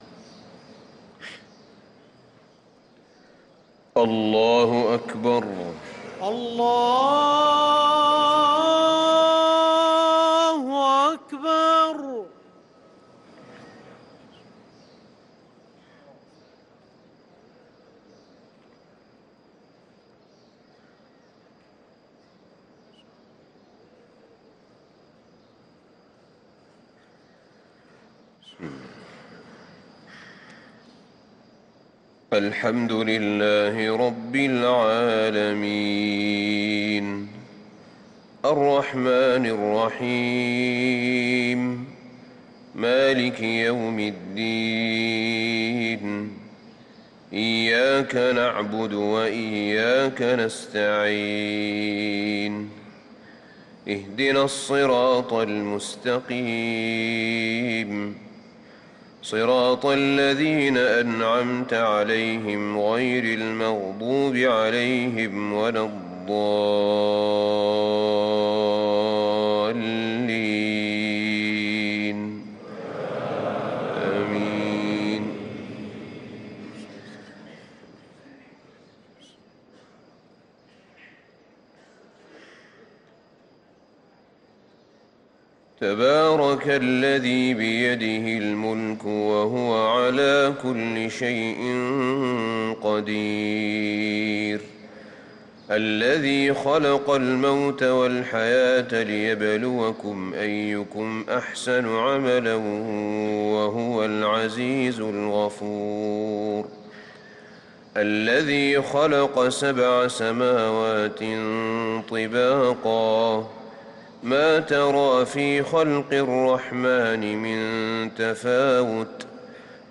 صلاة الفجر للقارئ أحمد بن طالب حميد 23 شعبان 1444 هـ